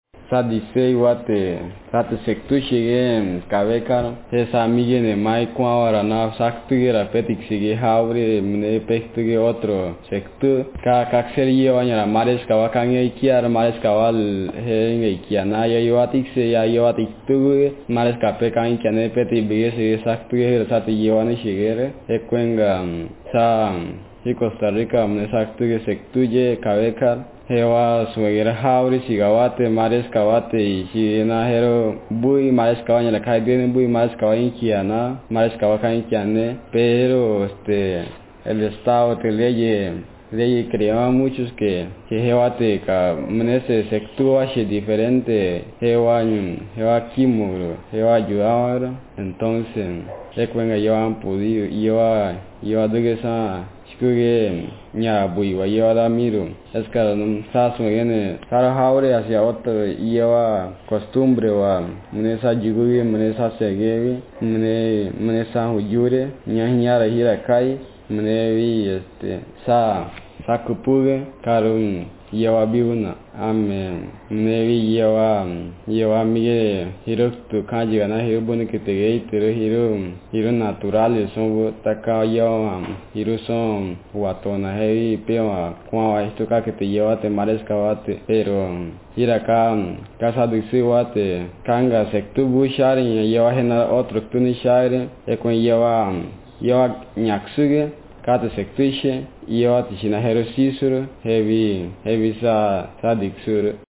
Speaker age19
Speaker sexm
Text genreprocedural